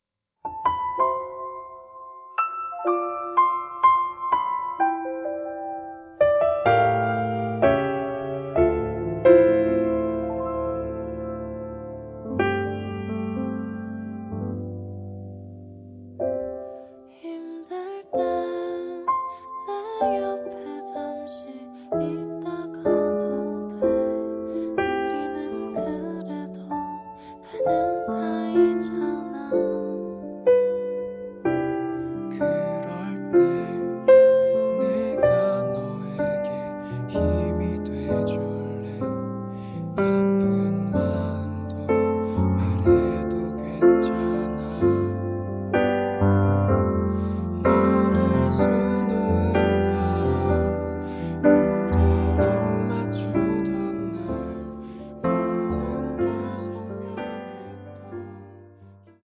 음정 -1키 3:38
장르 가요 구분 Voice Cut